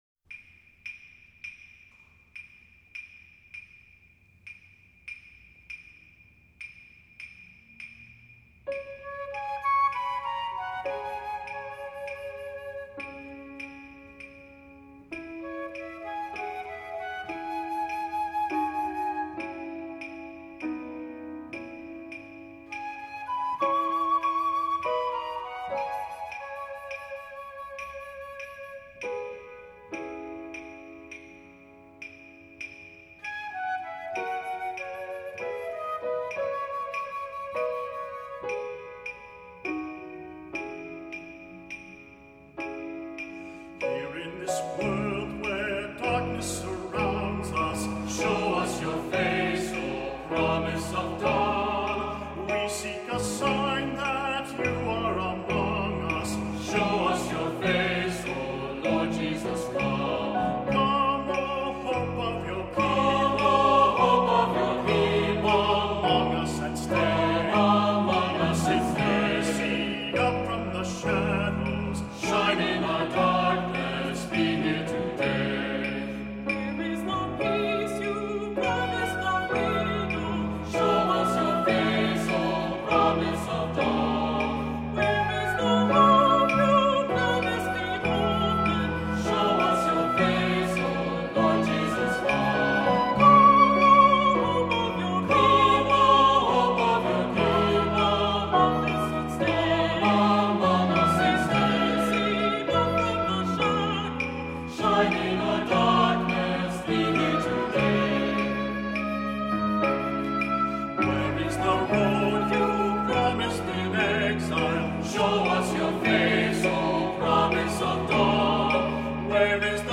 Voicing: SATB; Cantor; Priest; Assembly